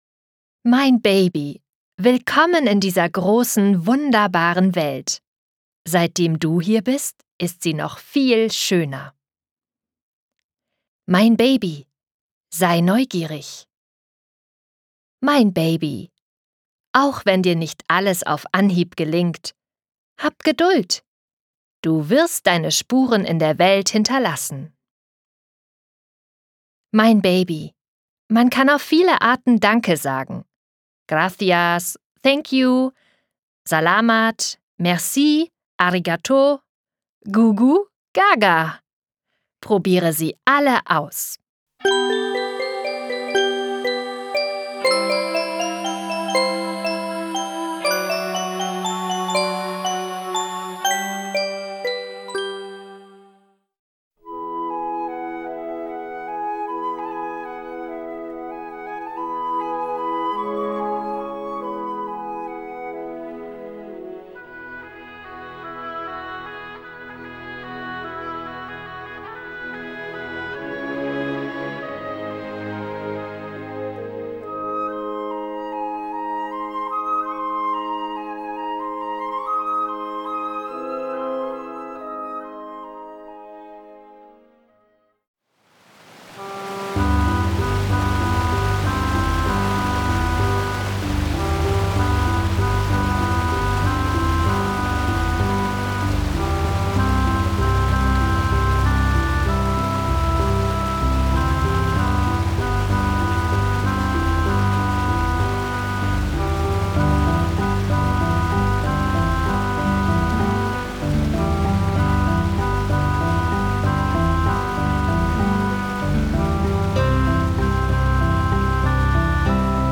Schlagworte Baby • babygeschenk • beruhigend • Entspannung • für babys • Geburt • Geburtstgsgeschenk • Kinderlieder • Klassische Musik • Liebeserklärung • zum Einschlafen